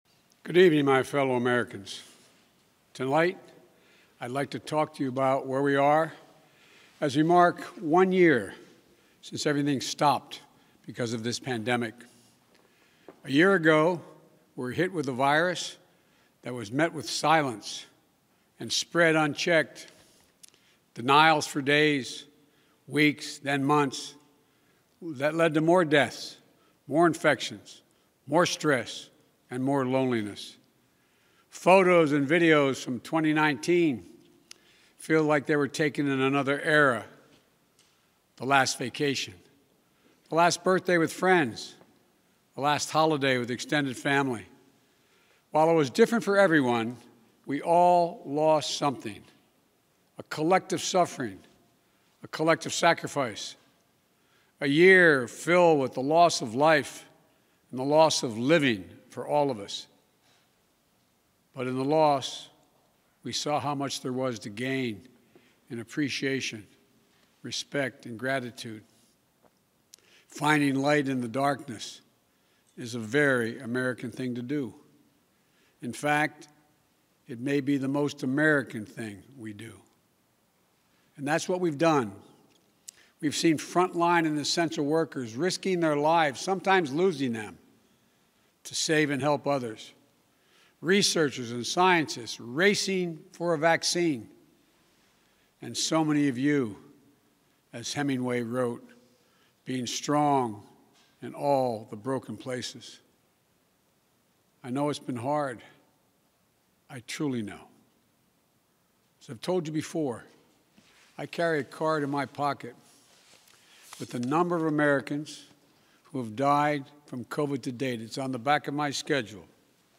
March 11, 2021: Remarks on the Anniversary of the Coronavirus Shutdown
President_Biden_Remarks_Anniversary_Coronavirus_Shutdown.mp3